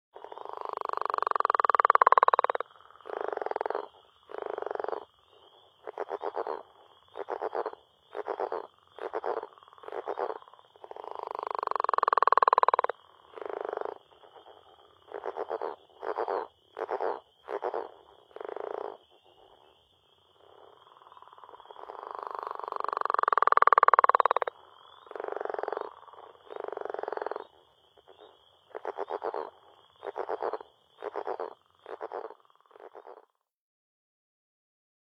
The less common Northern Leopard Frog (Lithobates pipiens) is another one of Vermont’s early callers, initiating courtship as early as March and continuing through the spring. Their calls typically have two parts, beginning with several clicks (described by some as a snore), followed by a brief pause, and then a series of chuckles or grunts similar to the sound of a hand rubbing a balloon.
Northern_leopard.mp3